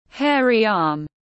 Lông tay tiếng anh gọi là hairy arm, phiên âm tiếng anh đọc là /ˈheə.ri ɑːm/.
Hairy arm /ˈheə.ri ɑːm/